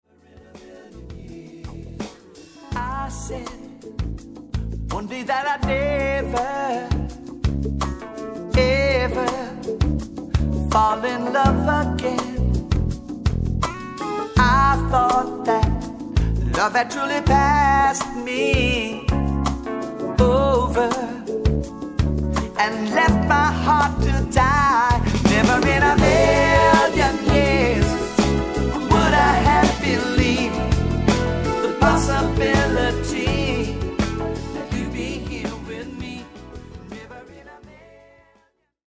フリー・ソウル・バンド